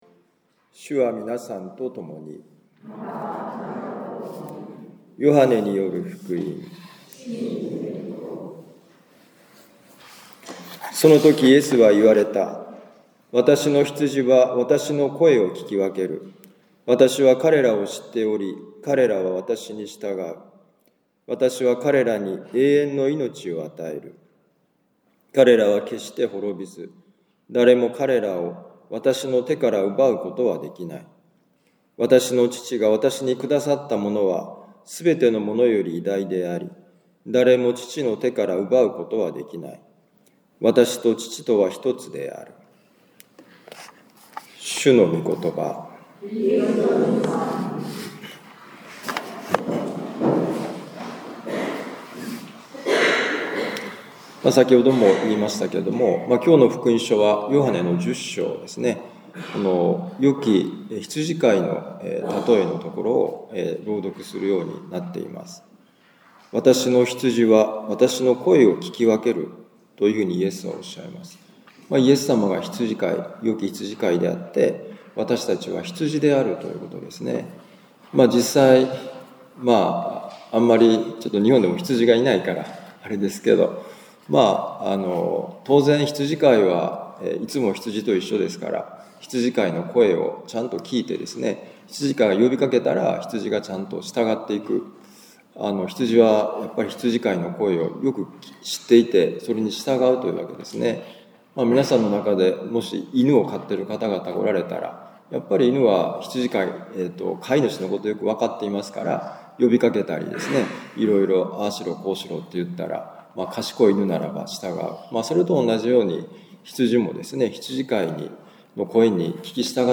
ヨハネ福音書10章27-30節「ファチマの祈りの場」2025年5月11日復活節第4主日のミサ カトリック長府教会
長府教会もファチマのような清い祈りの場になってほしいと願っています。（教会祭の日のミサ）